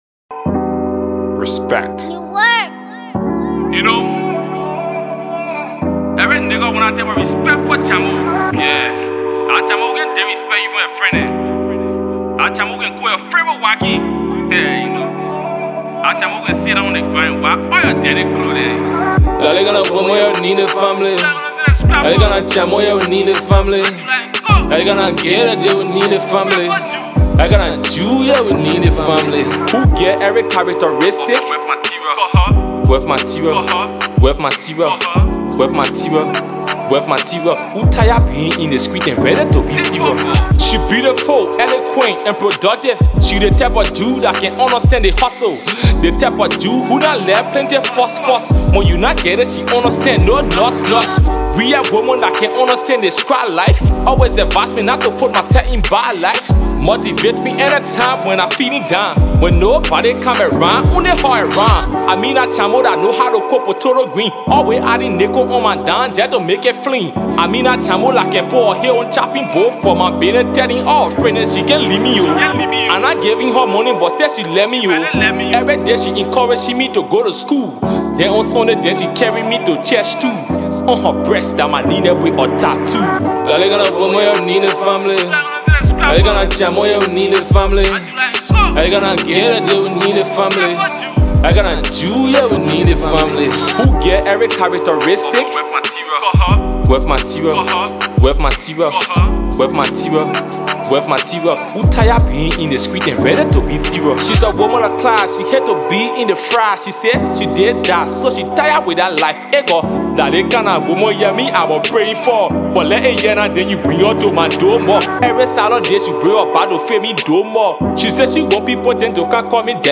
Freestyling in his colloquial with style